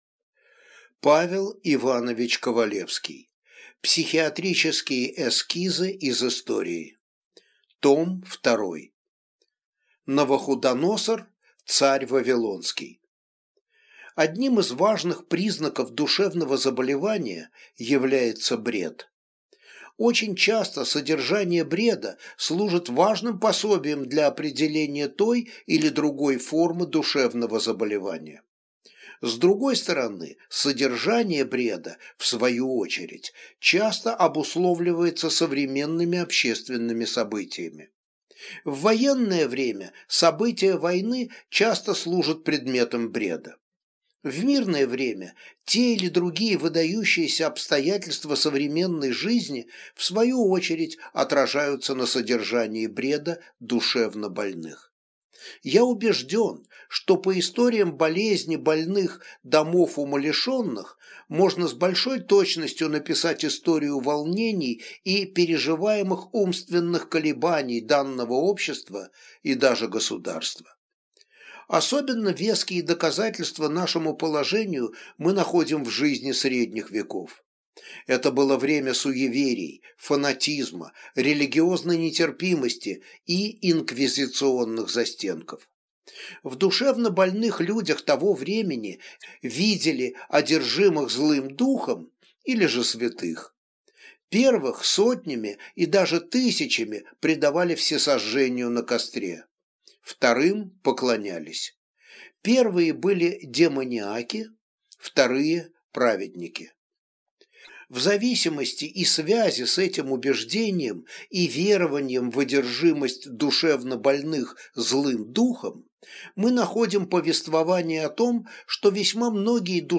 Аудиокнига Психиатрические эскизы из истории. Том 2 | Библиотека аудиокниг